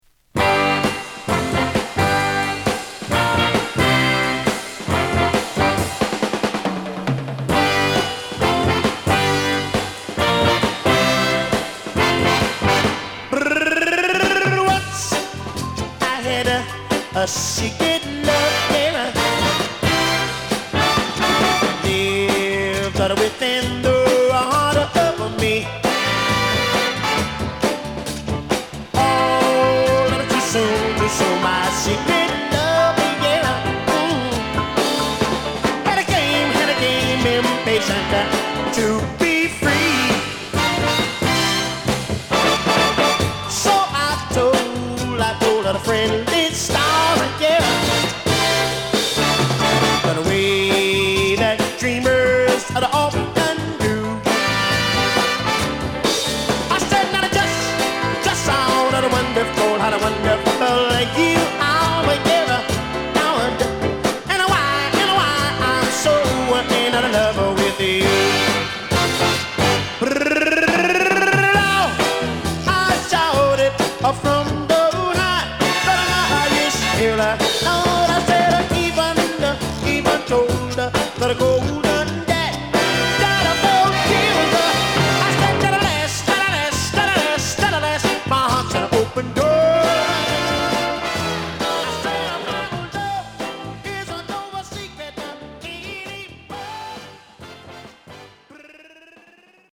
テンション高めのスキャットを交えたヴォーカルが晴れ晴れとしたホーン等が絡むトラックに乗るR&Bチューン！